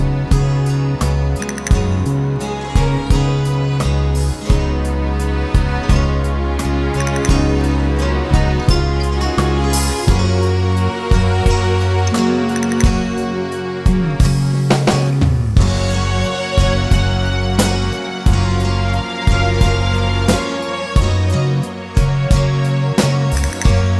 With Harmony Pop (1960s) 1:56 Buy £1.50